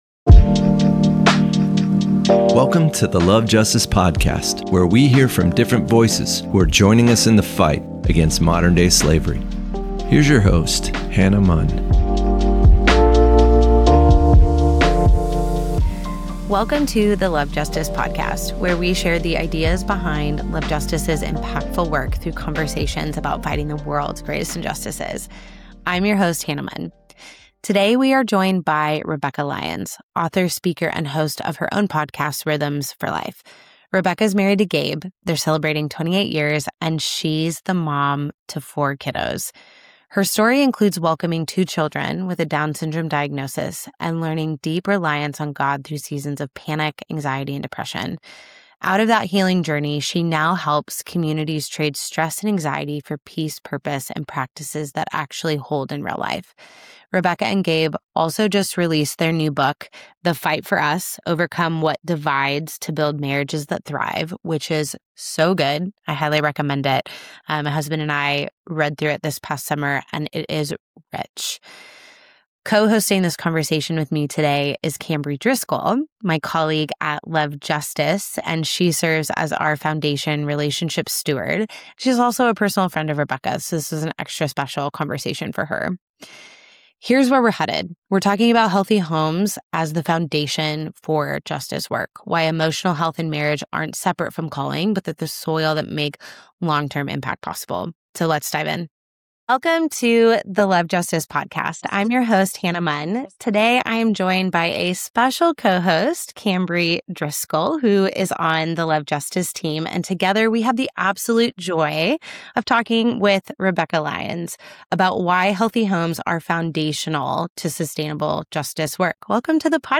This conversation resonates deeply with the mission of Love Justice International, where frontline work against trafficking demands not only courage and excellence, but also resilience, repa